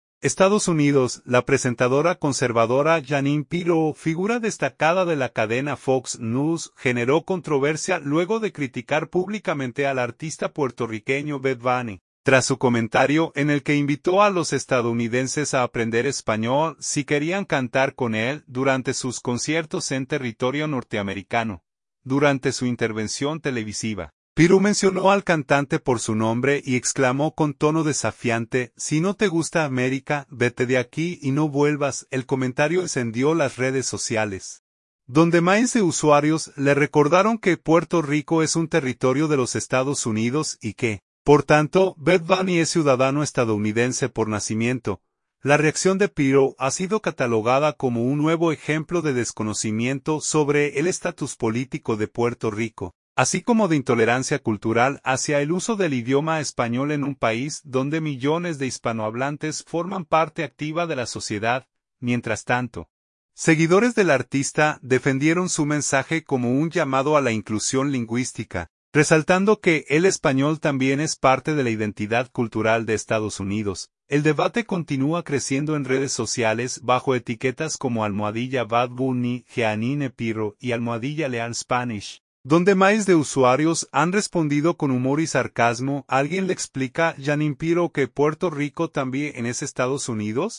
Durante su intervención televisiva, Pirro mencionó al cantante por su nombre y exclamó con tono desafiante: